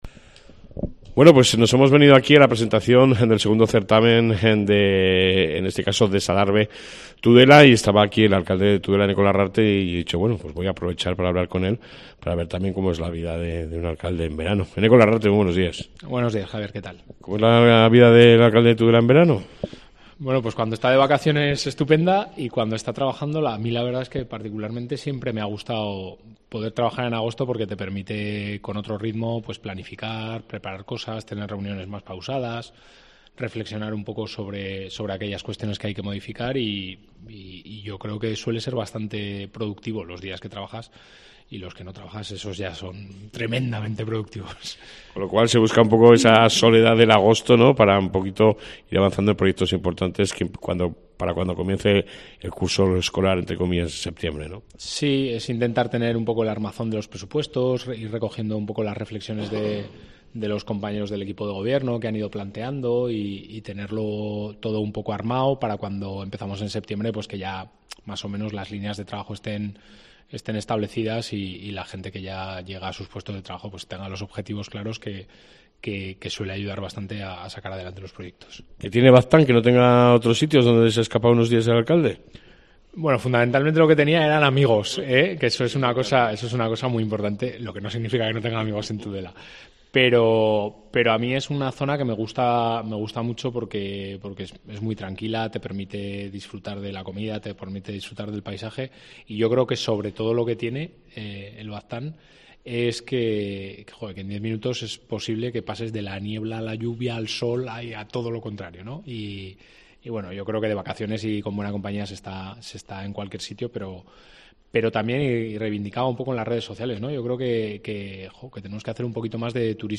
Aprovechando la presentación de la 2ª edición del Des-Adarve Tudela (certamen de Arte Efímero) me he quedado a solas con el Alcalde Eneko Larrarte y hemos hablado de todo un poco.